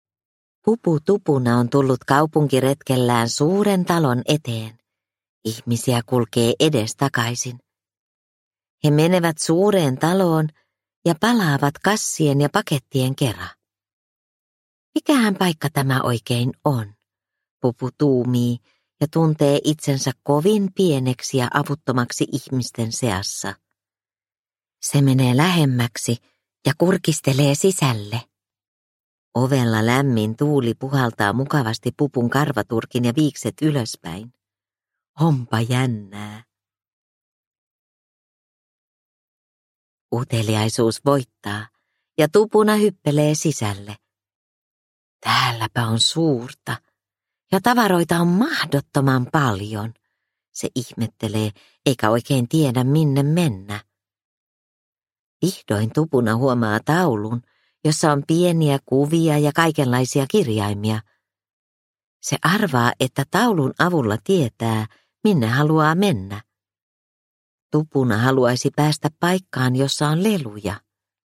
Pupu Tupuna tavaratalossa – Ljudbok – Laddas ner